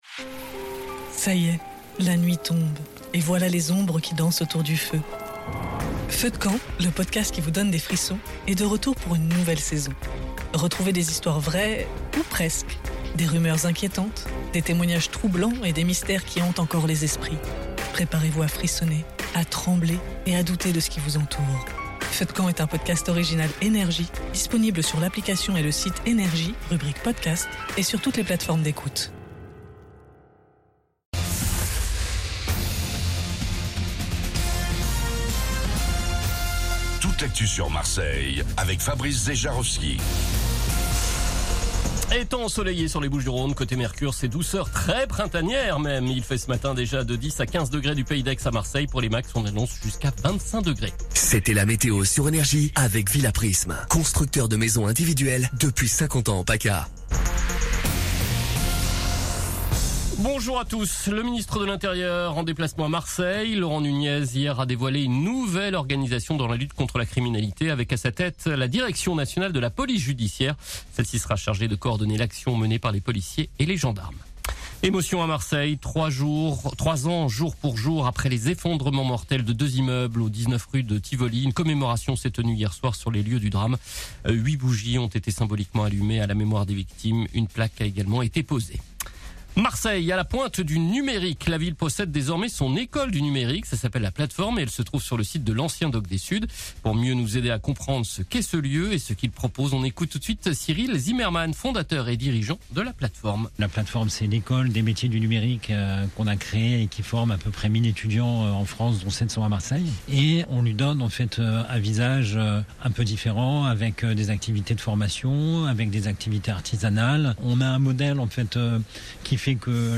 Réécoutez vos INFOS, METEO et TRAFIC de NRJ MARSEILLE du vendredi 10 avril 2026 à 08h00